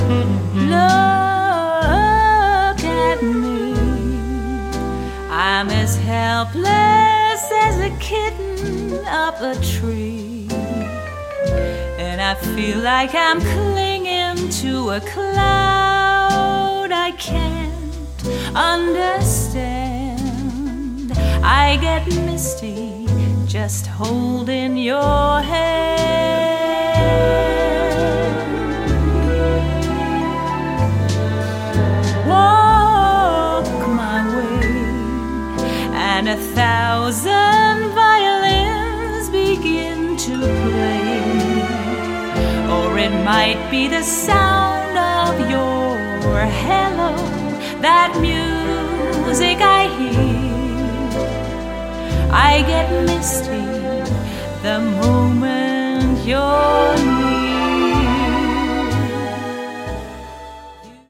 Pop/easylistening